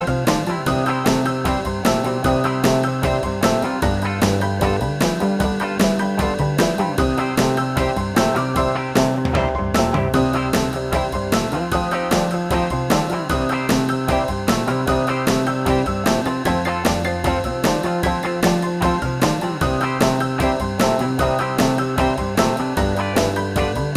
Minus Lead Guitar Rock 'n' Roll 2:57 Buy £1.50